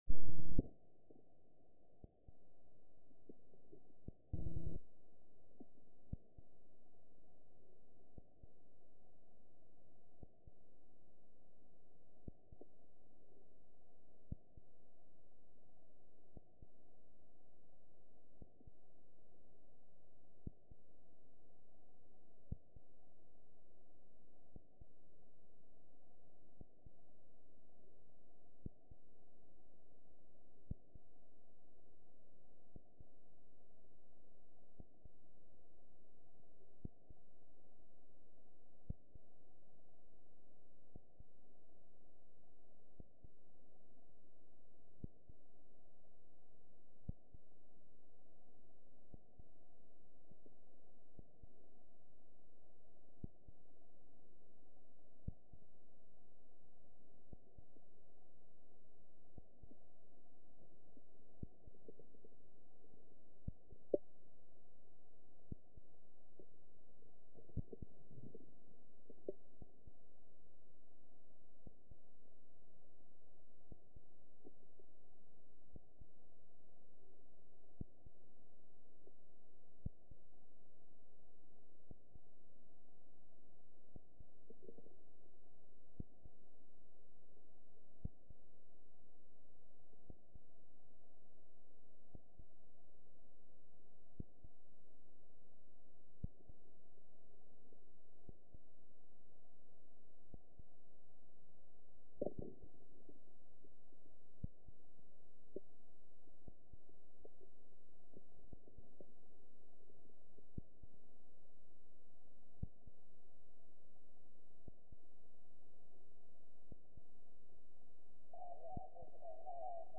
Here are some recordings of the contacts that I have made true satellites.